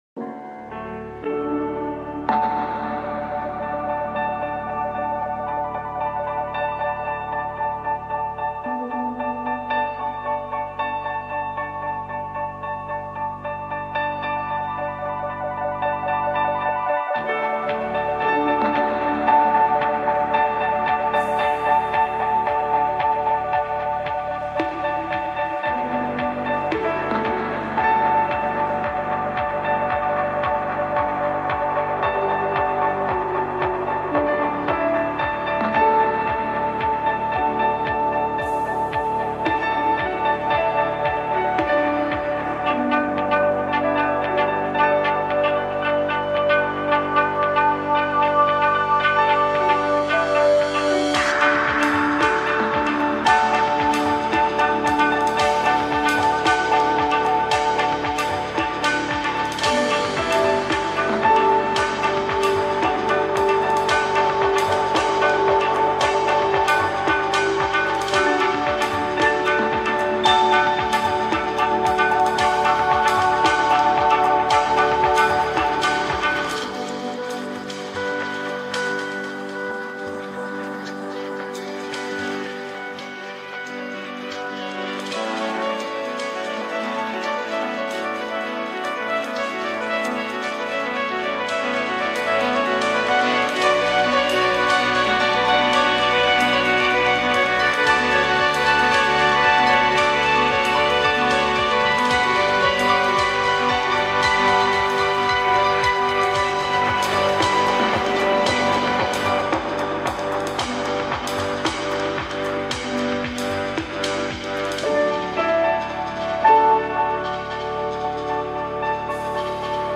BoardingMusic[2].ogg